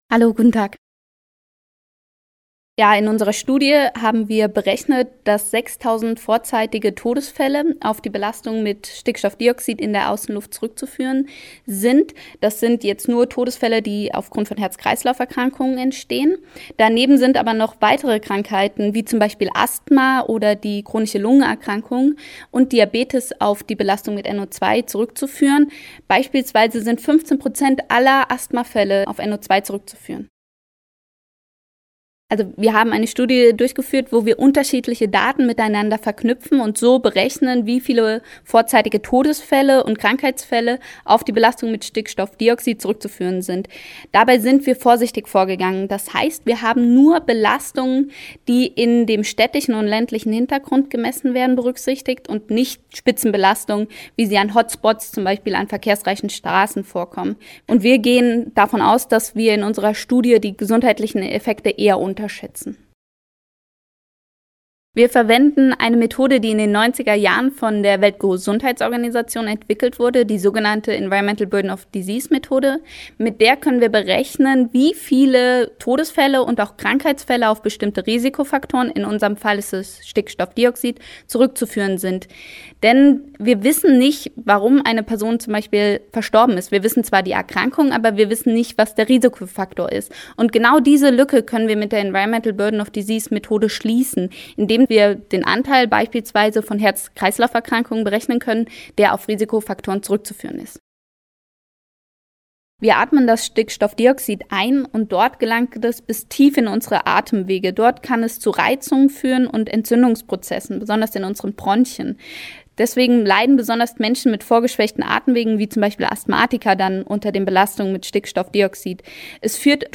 Interview: 2:23 Minuten